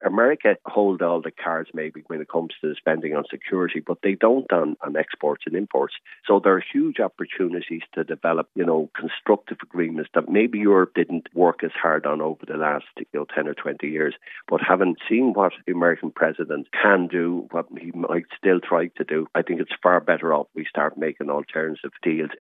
Bertie Ahern says the crisis is also presenting new opportunities: